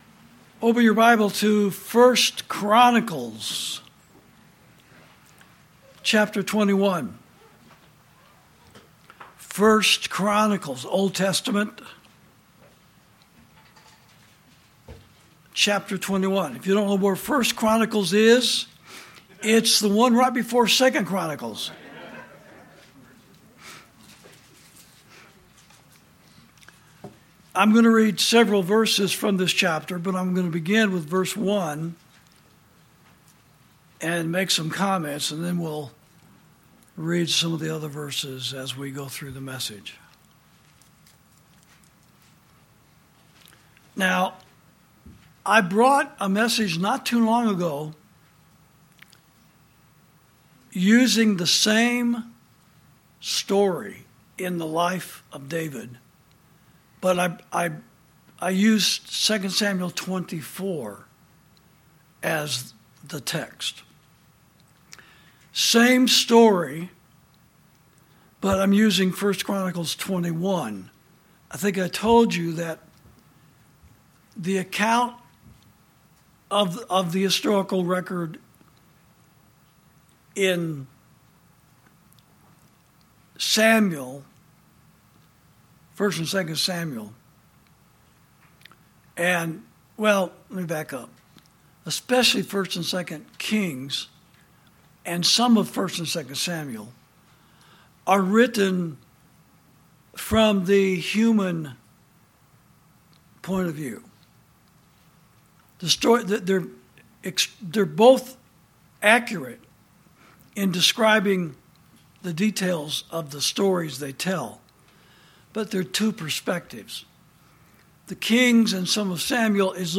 Sermons > Satan: Blessed Are The Warmongers